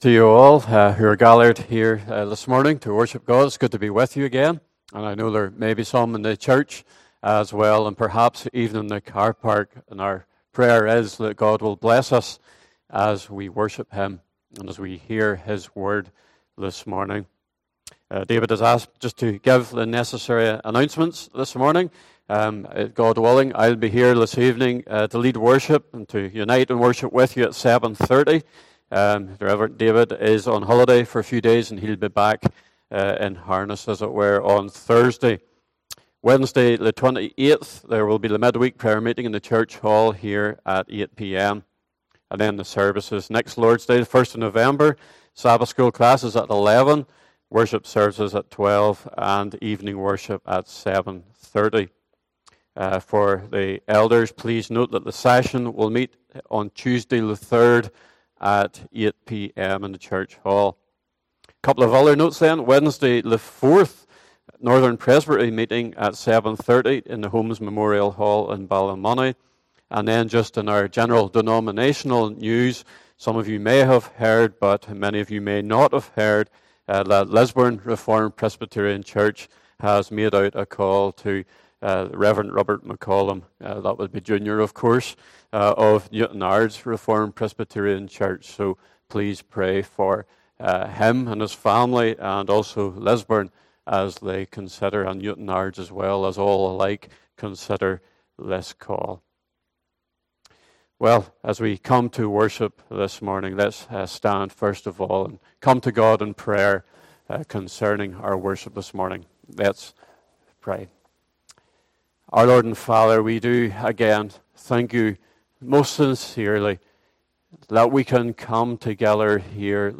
33 Service Type: Morning Service Bible Text